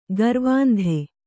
♪ garvāndhe